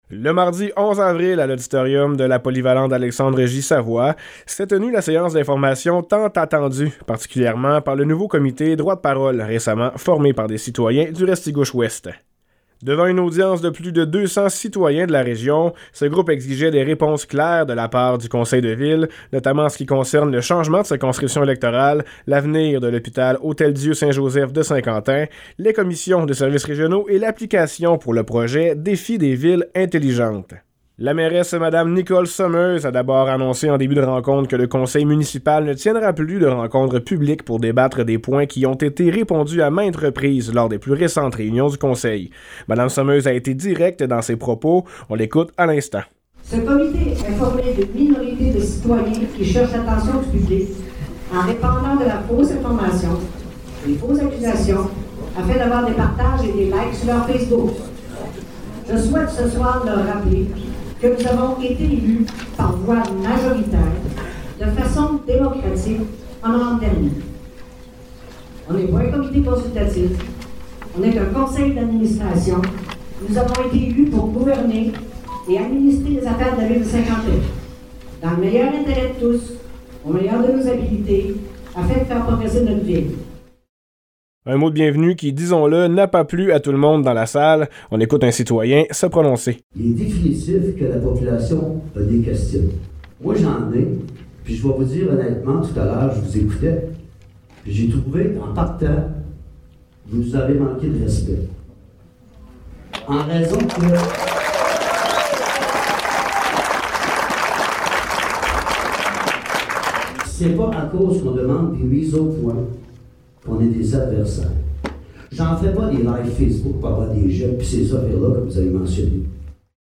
Voici le résumé de la séance d'information tant attendue qui a eu lieu hier soir à la Polyvalente Alexandre J. Savoie de Saint-Quentin.
Pour une énième fois, le conseil municipal de la Ville de Saint-Quentin était rassemblé devant une foule pour répondre aux questions de la population.
reportage-rencontre-11-avril.mp3